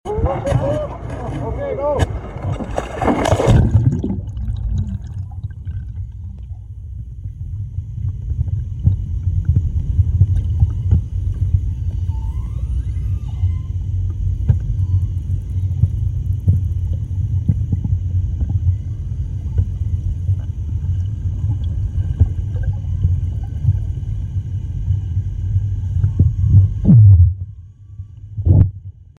Orca Singing May Be The Sound Effects Free Download